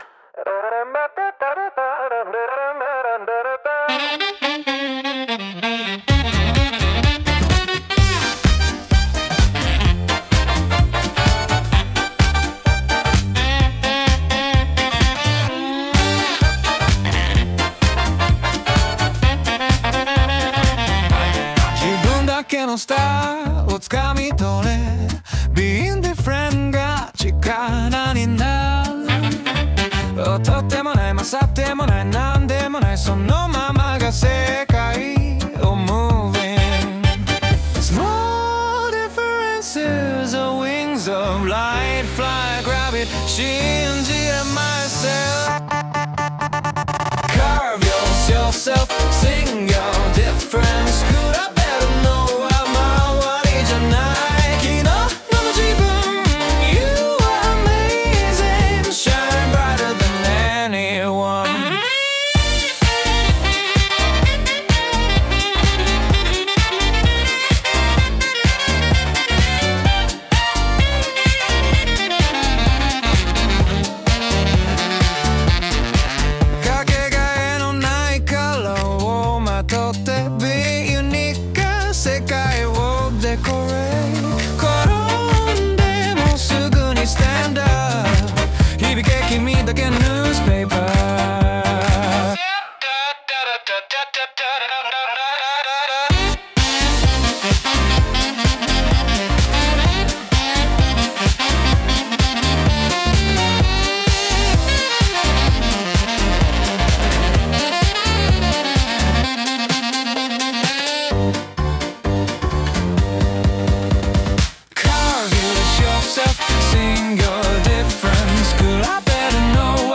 歌ものフリー素材 bgm音楽 無料ダウンロード 商用・非商用ともに登録不要で安心してご利用いただけます。
男性ボーカル
イメージ：スウィング・ジャズ,男性ボーカル,アップライトベース,エレクトロ